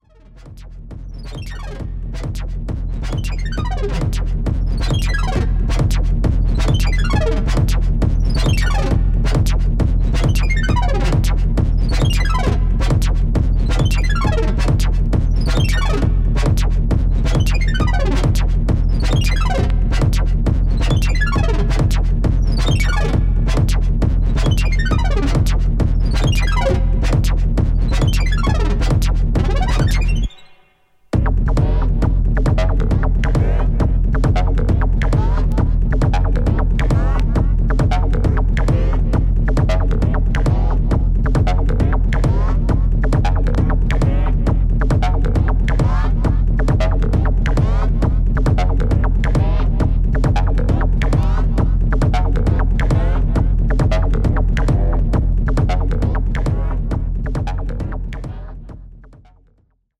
ホーム ｜ HOUSE / TECHNO > HOUSE